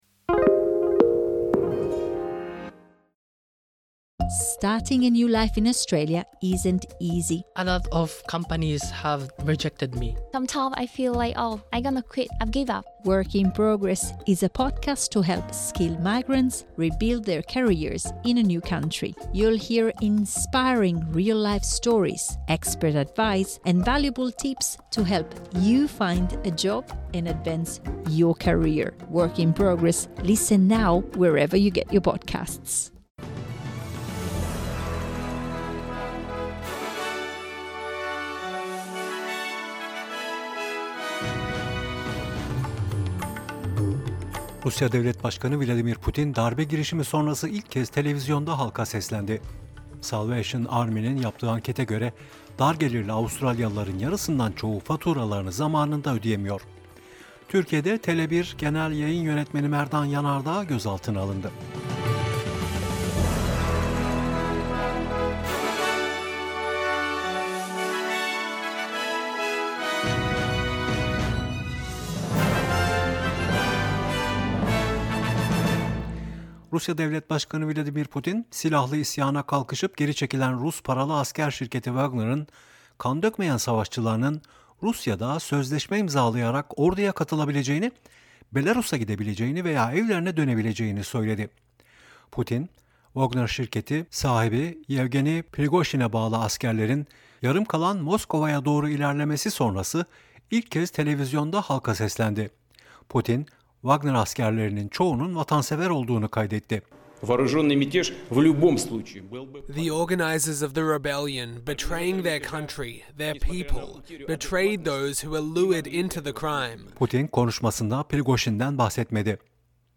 SBS Türkçe Haberler 27 Haziran